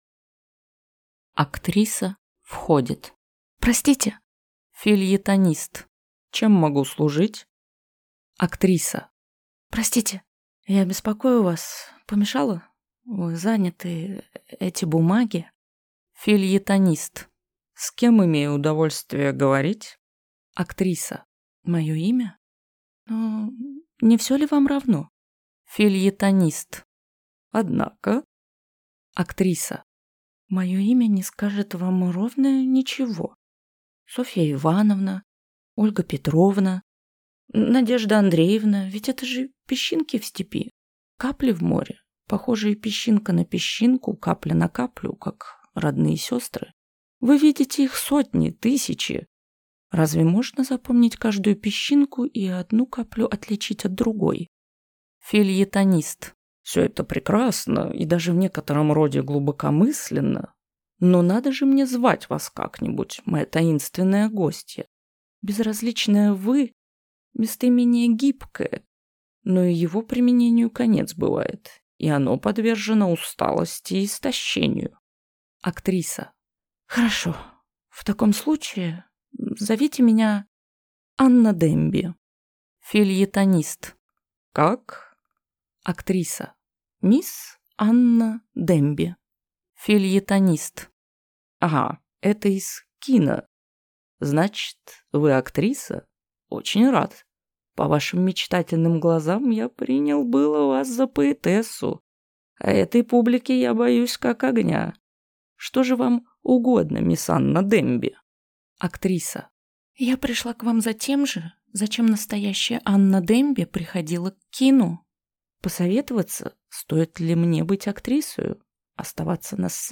Аудиокнига Анна Дэмби | Библиотека аудиокниг